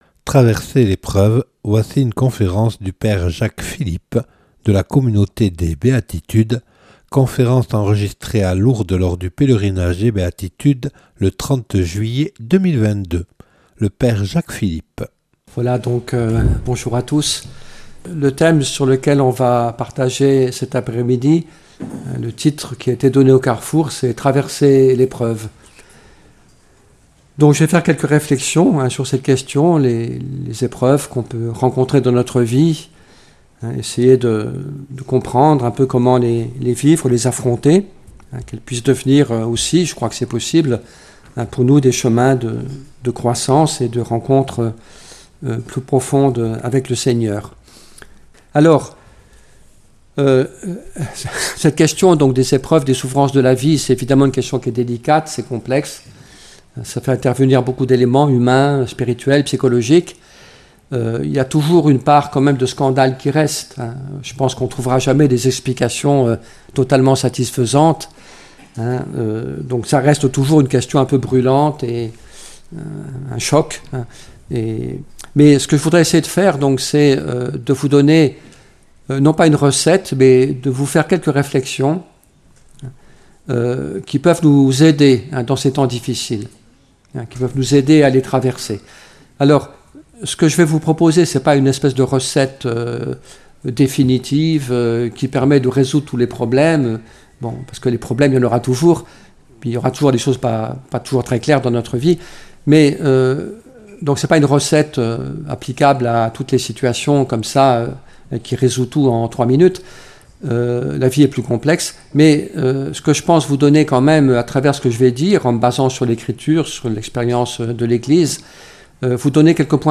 (Enregistré le 30/07/2022 à Lourdes lors du Pèlerinage des Béatitudes).